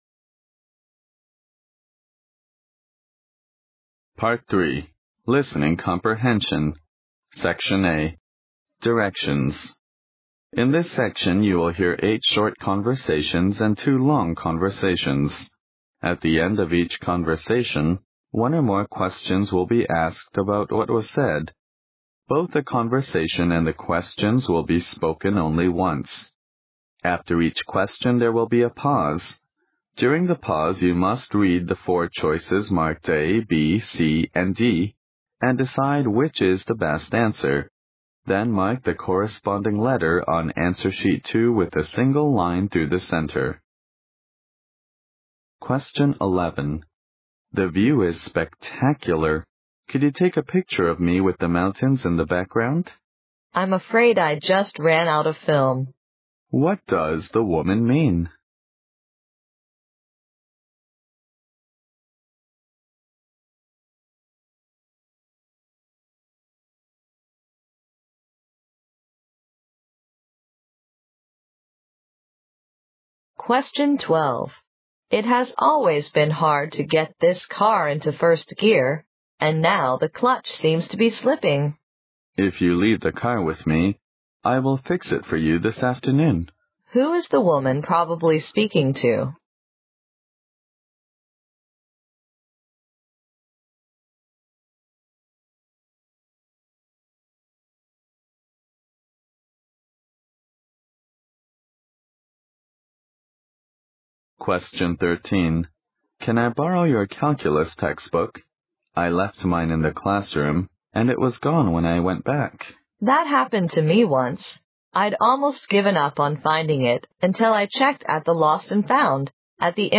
Part III Listing Comprehension (35 minutes)